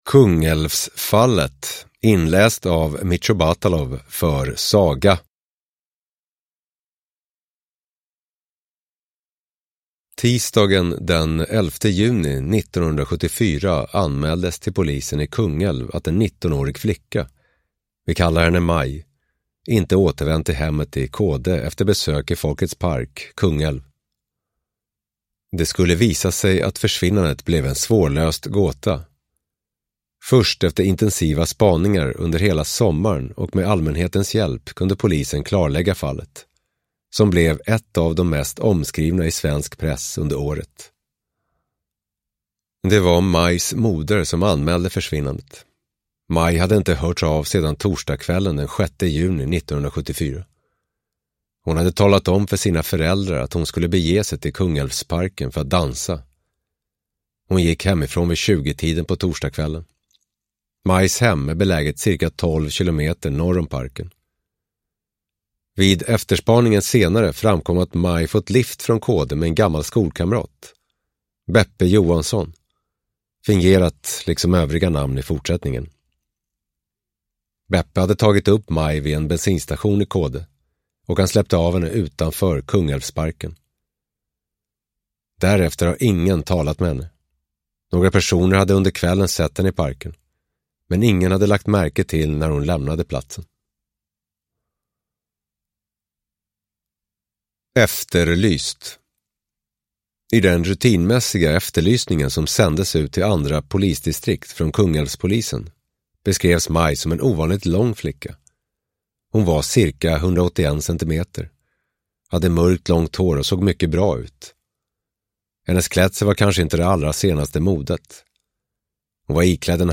Svårlösta fall i svensk brottshistoria – Ljudbok